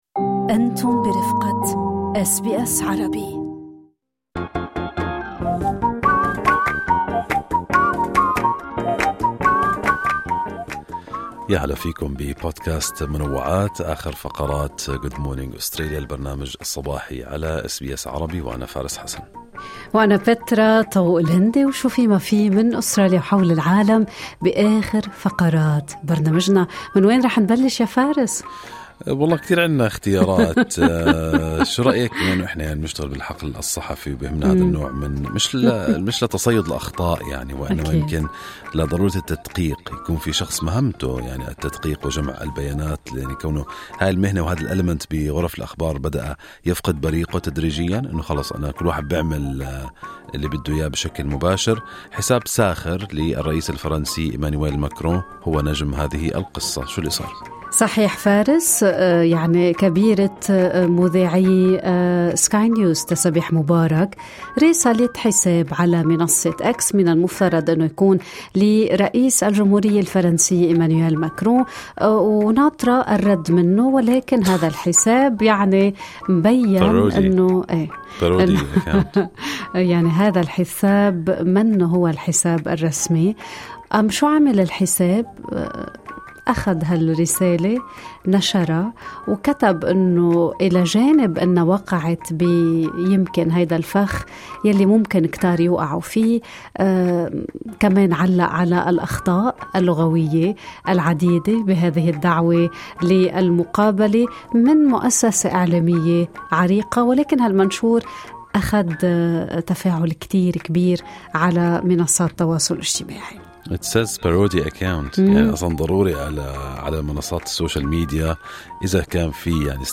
نقدم لكم فقرة المنوعات من برنامج Good Morning Australia التي تحمل إليكم بعض الأخبار والمواضيع الخفيفة.